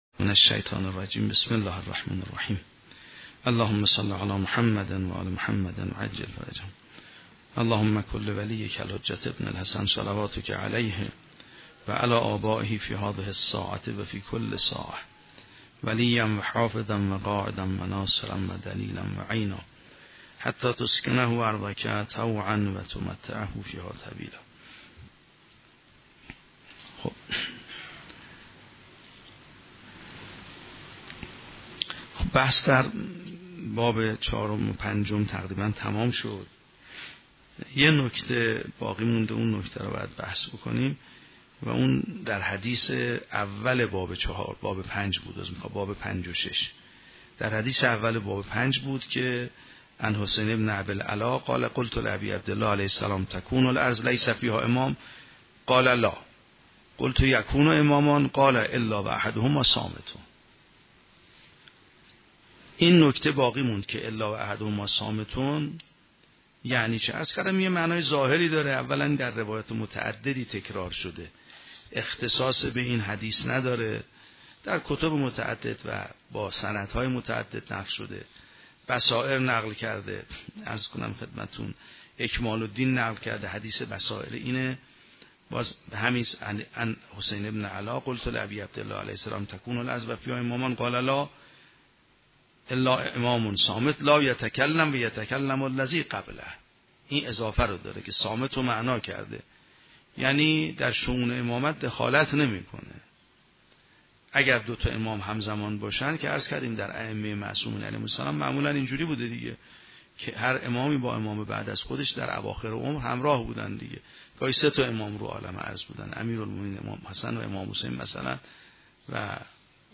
شرح و بررسی کتاب الحجه کافی توسط آیت الله سید محمدمهدی میرباقری به همراه متن سخنرانی ؛ این بخش : تبیین حقیقت وحدت حجت الهی در زمین و بررسی مفاد روایات